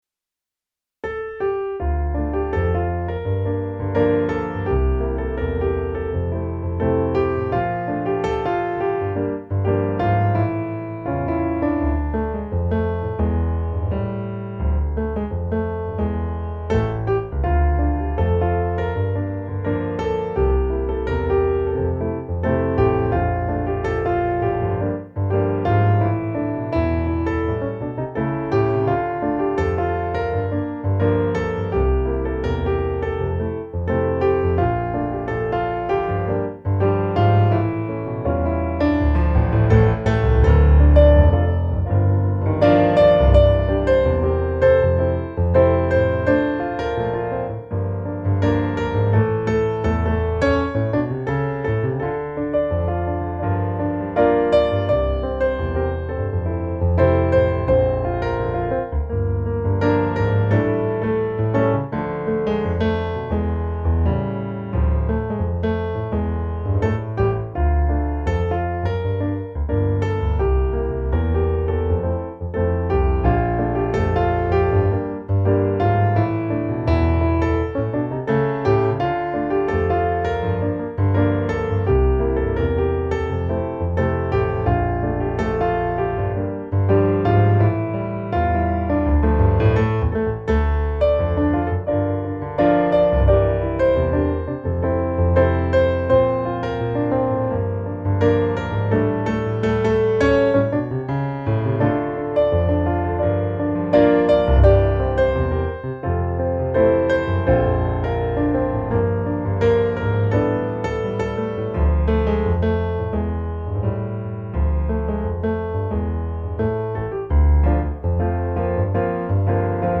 Du är helig, du är hel - musikbakgrund
Musikbakgrund Psalm